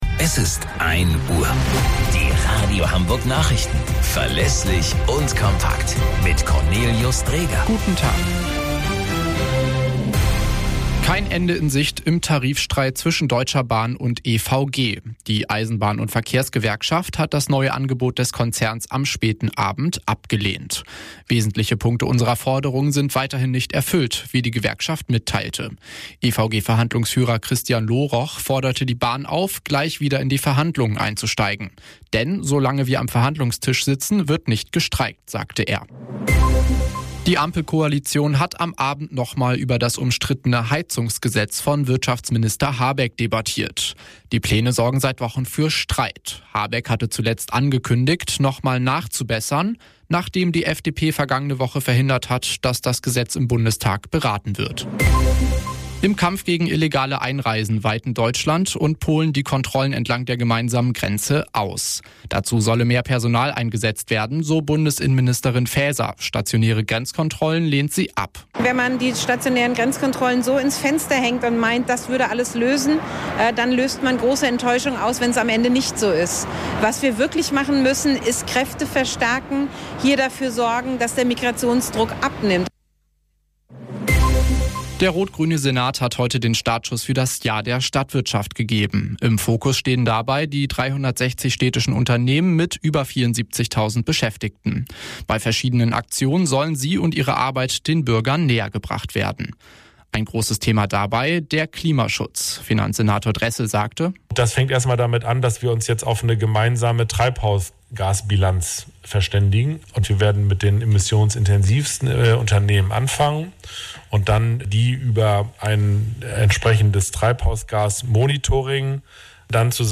Radio Hamburg Nachrichten vom 31.05.2023 um 02 Uhr - 31.05.2023